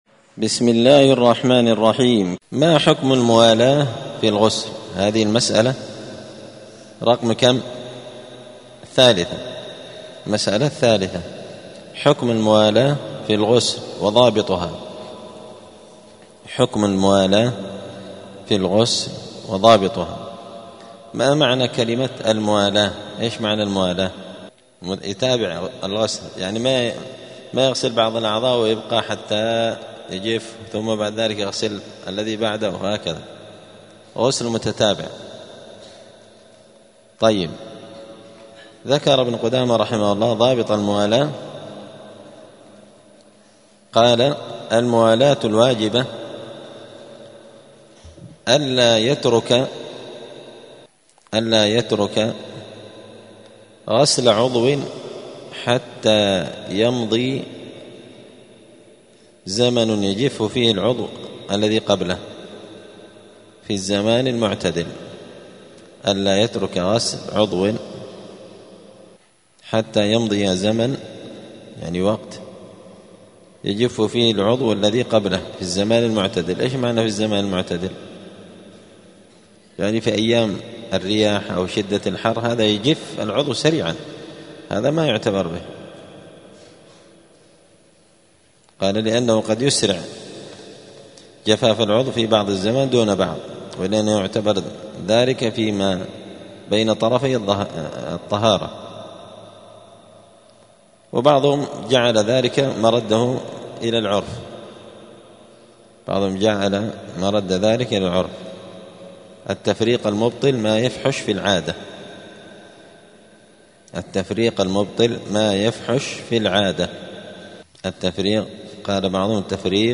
دار الحديث السلفية بمسجد الفرقان قشن المهرة اليمن
*الدرس الثاني والثمانون [82] {باب صفة الغسل حكم الموالاة في الغسل وضابطها}*